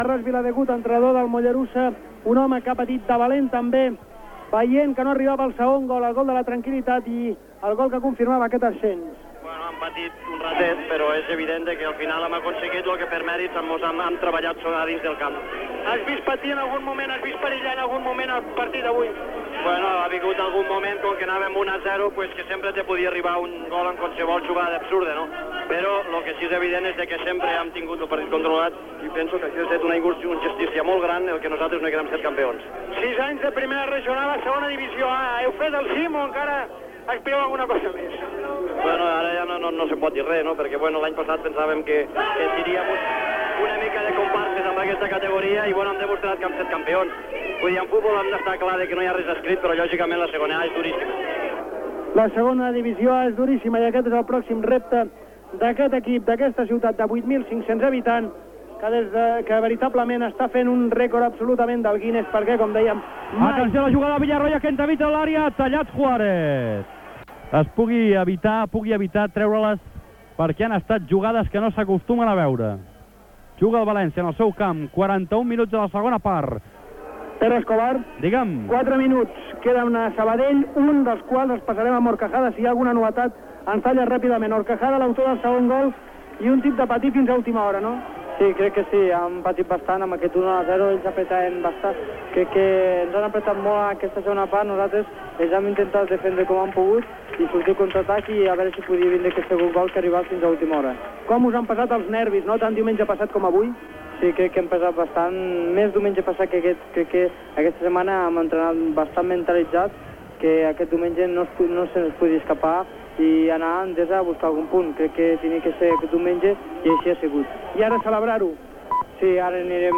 Transmissió del partit de futbol masculí entre el Mollerussa i el Mirandès.
Esportiu